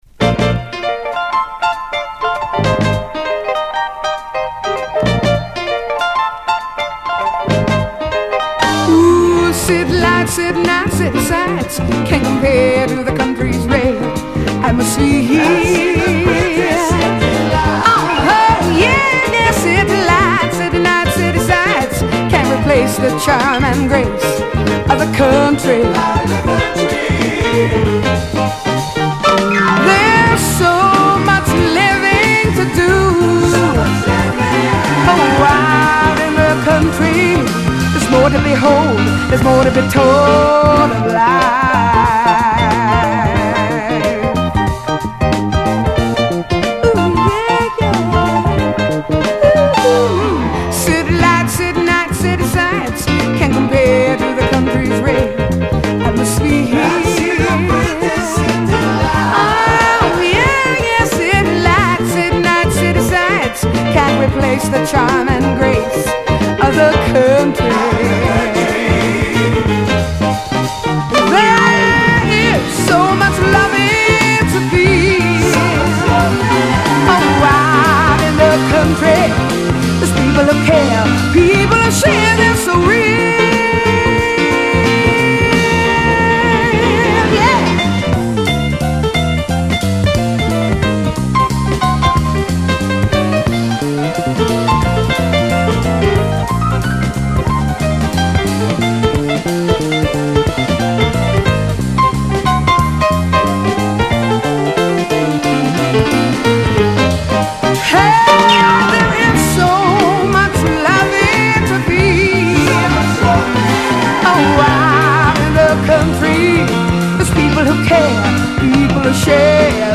ハワイの女性シンガー
開放感溢れる伸び伸びとした歌いっぷりにソウルを感じずにはいられません。